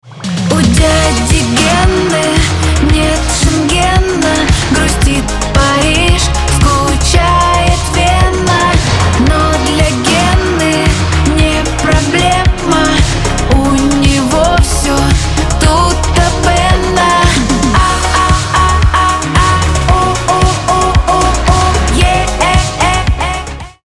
• Качество: 320, Stereo
женский голос